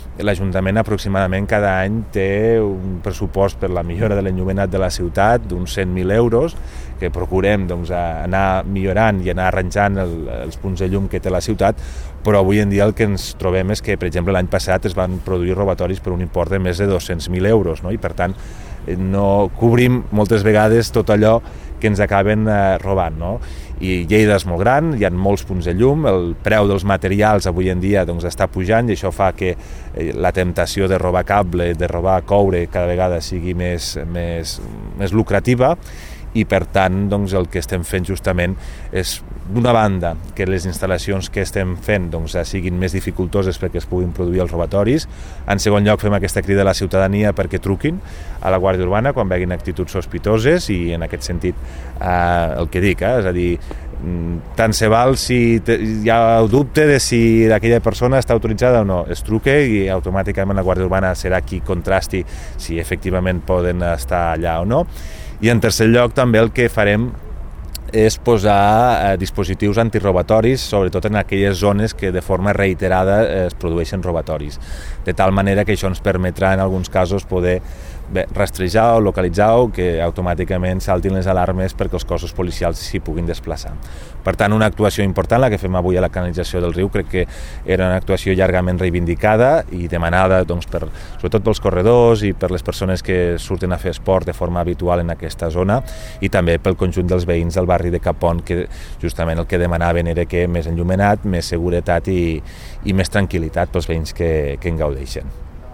tall-de-veu-de-lalcalde-accidental-toni-postius